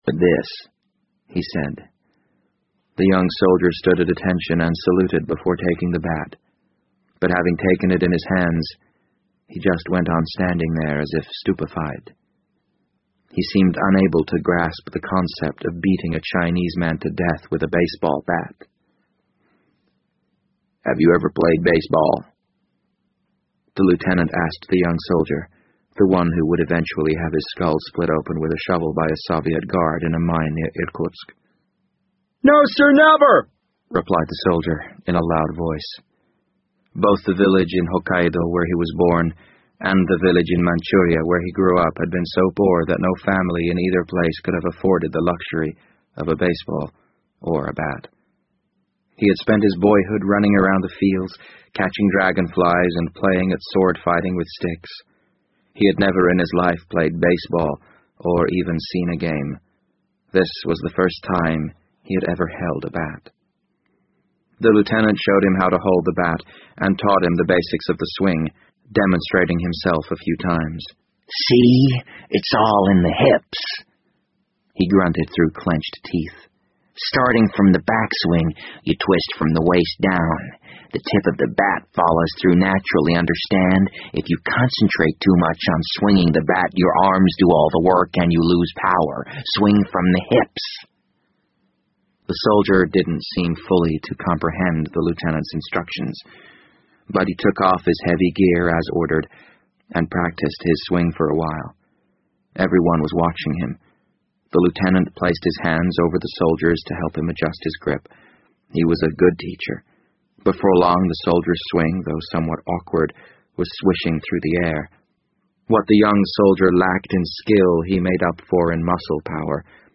BBC英文广播剧在线听 The Wind Up Bird 013 - 11 听力文件下载—在线英语听力室